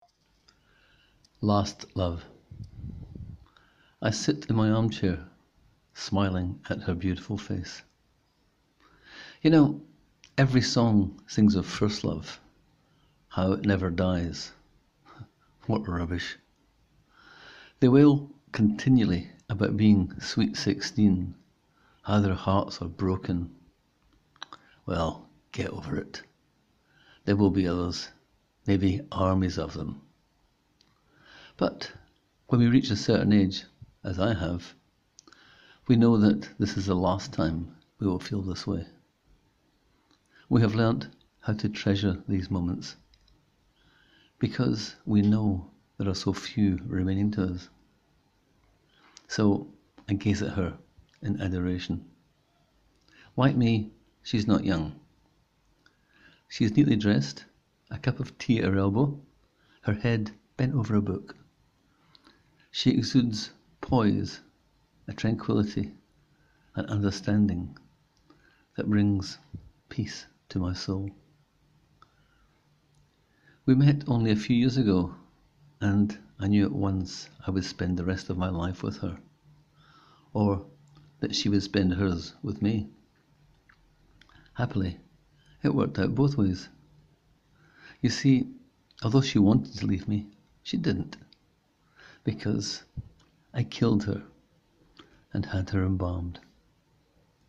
Click here to hear the story read by the author: